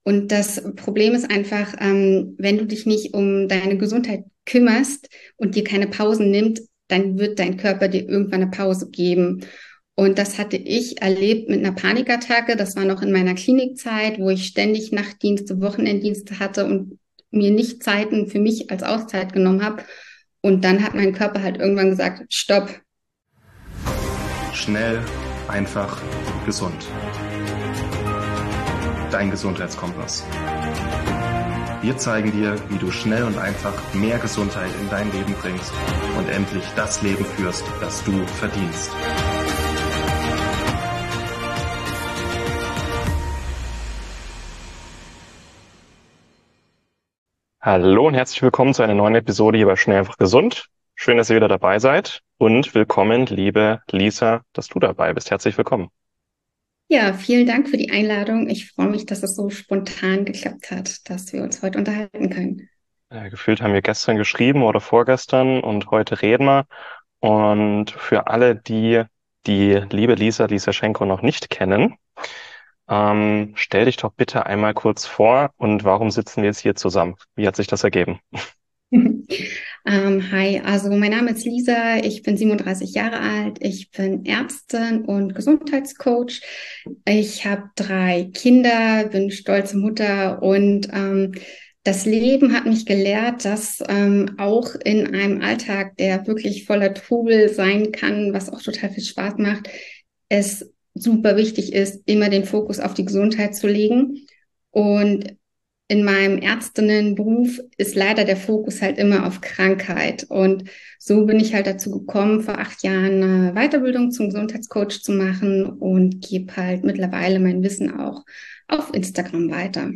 Ein Interview mit vielen Themen und Exkursen und 1000 wertvollen Tipps für jeden Tag.